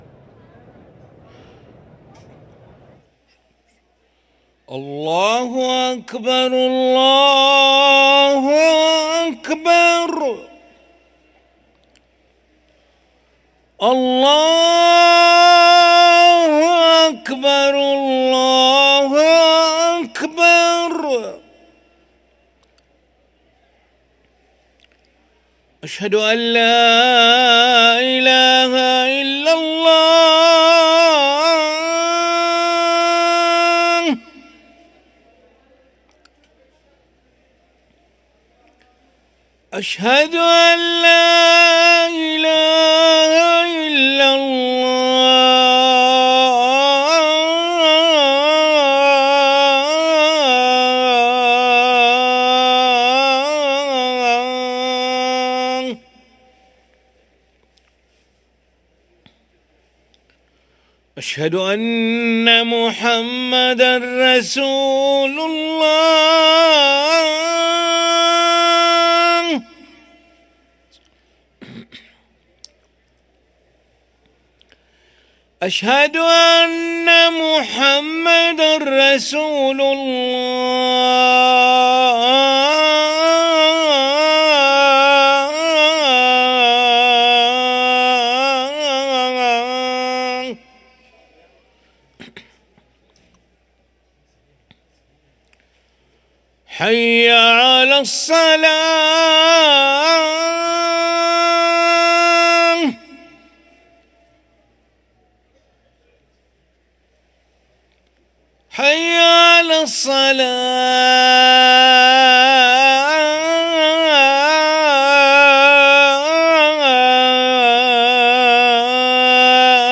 أذان العشاء للمؤذن علي ملا الخميس 23 جمادى الأولى 1445هـ > ١٤٤٥ 🕋 > ركن الأذان 🕋 > المزيد - تلاوات الحرمين